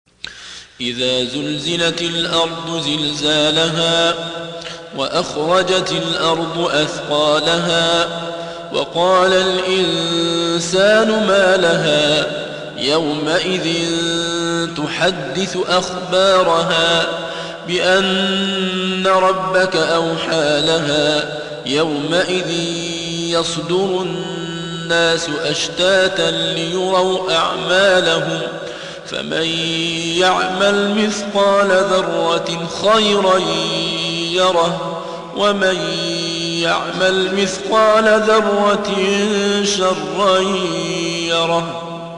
99. Surah Az-Zalzalah سورة الزلزلة Audio Quran Tarteel Recitation
Surah Repeating تكرار السورة Download Surah حمّل السورة Reciting Murattalah Audio for 99. Surah Az-Zalzalah سورة الزلزلة N.B *Surah Includes Al-Basmalah Reciters Sequents تتابع التلاوات Reciters Repeats تكرار التلاوات